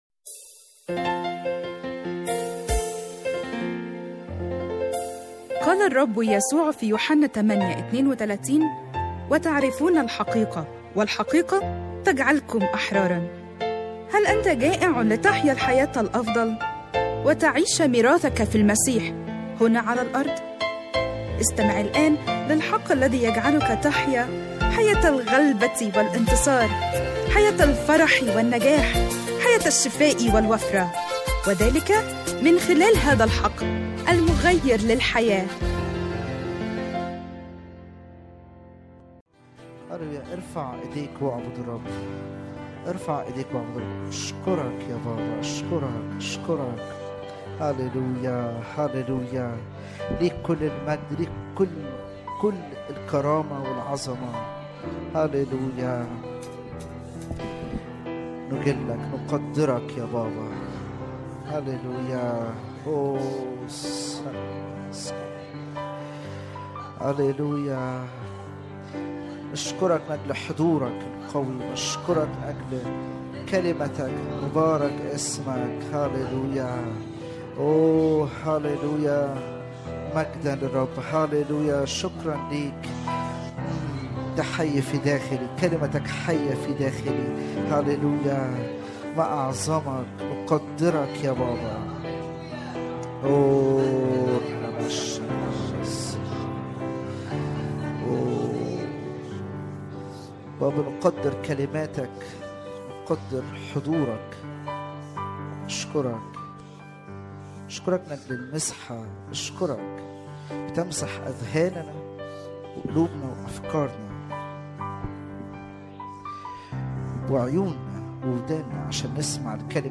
العظة مكتوبة